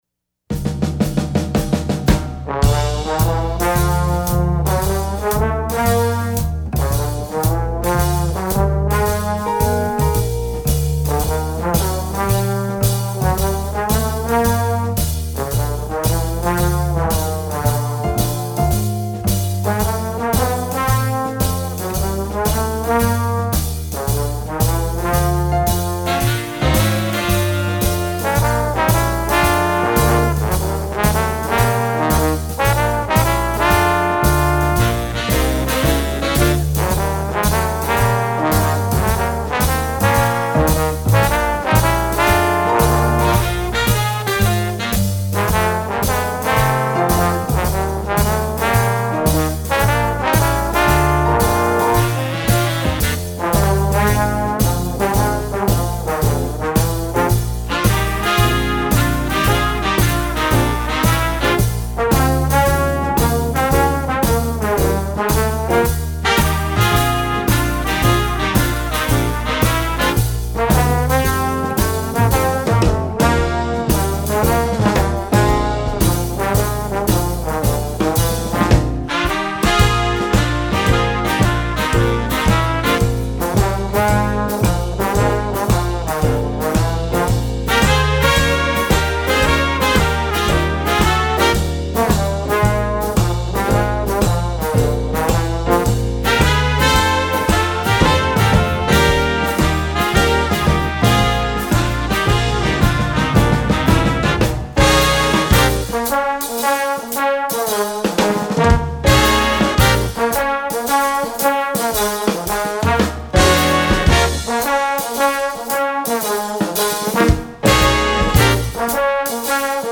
Voicing: Jazz Ensemble